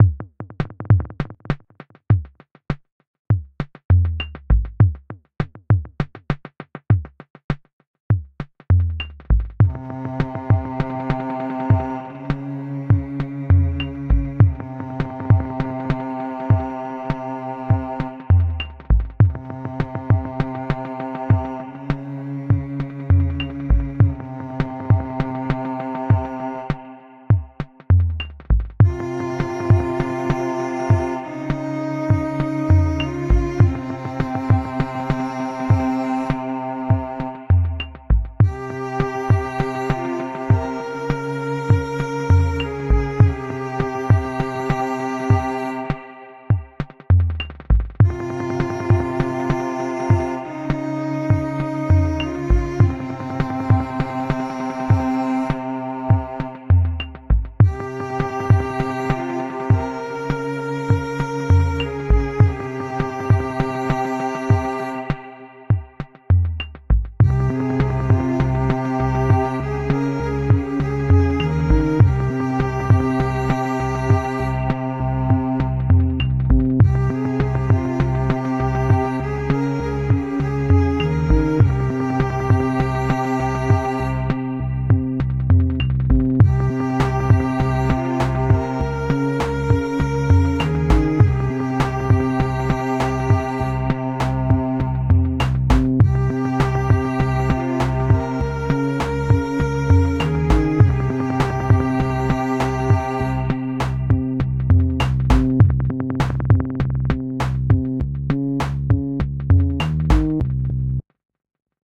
Pieza de Ambient
Música electrónica
melodía
repetitivo
rítmico
sintetizador